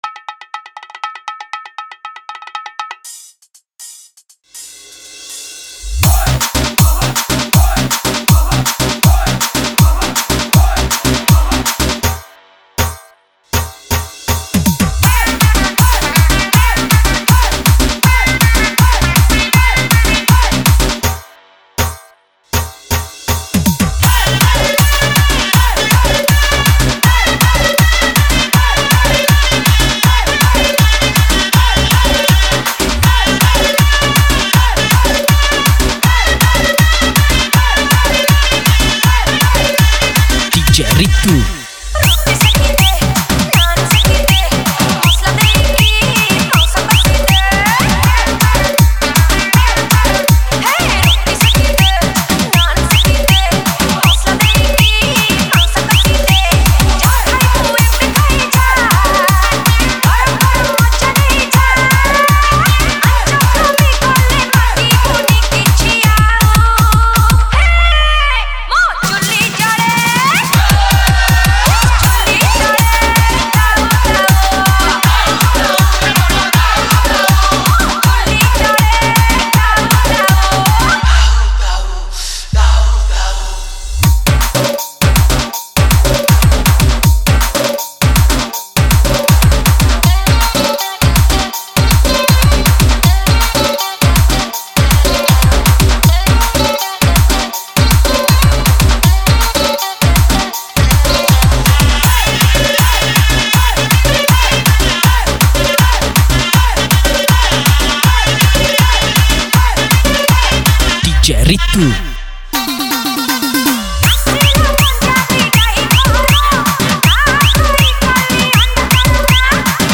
• Category:Odia New Dj Song 2017